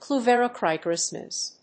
意味・対訳 クライベラ‐クリオクレッセンス